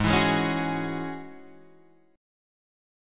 Ab7sus4#5 chord